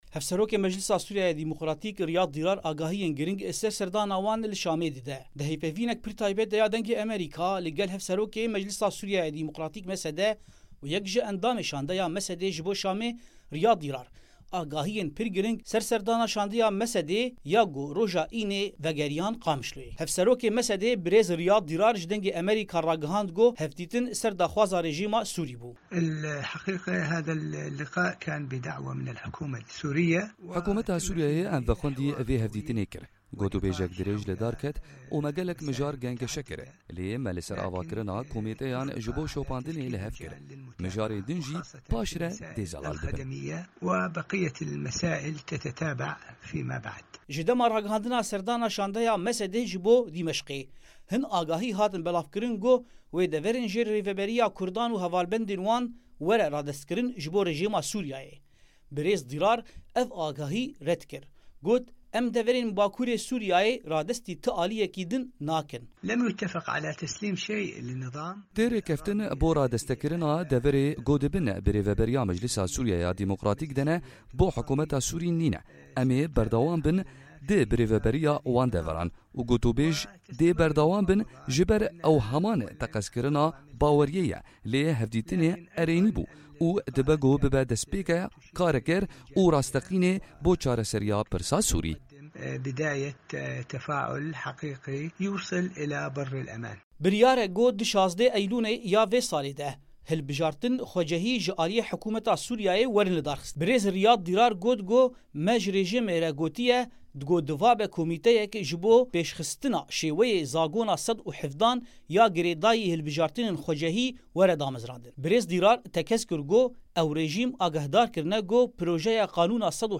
لە وتووێژێکی تایبەتیدا لەگەڵ بەشی کوردی دەنگی ئەمەریکا هاوسەرۆکی ئەنجومەنی سوریای دیموکرات و یەکێک لە ئەندامە باڵاکانی شندەکەیان بۆ دیمەشق زانیاری گرنگی لەو بارەیەوە ڕاگەیاند.